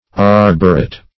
Meaning of arboret. arboret synonyms, pronunciation, spelling and more from Free Dictionary.